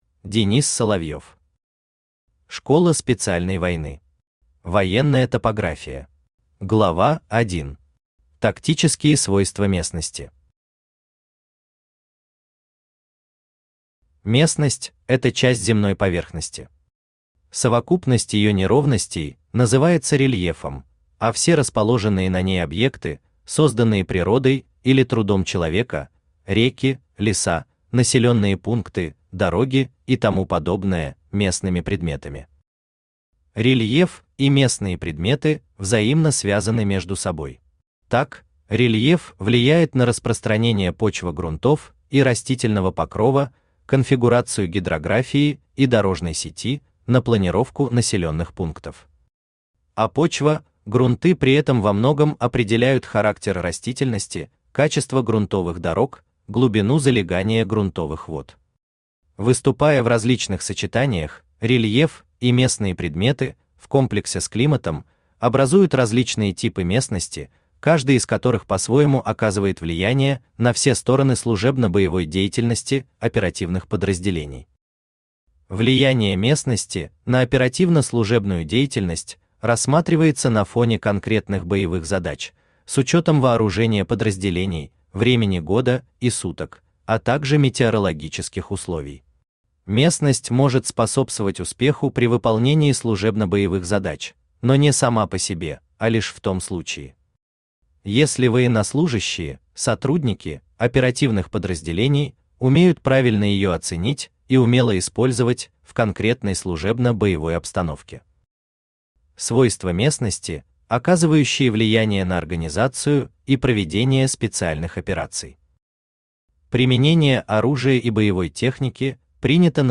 Аудиокнига Школа специальной войны. Военная топография | Библиотека аудиокниг
Военная топография Автор Денис Соловьев Читает аудиокнигу Авточтец ЛитРес.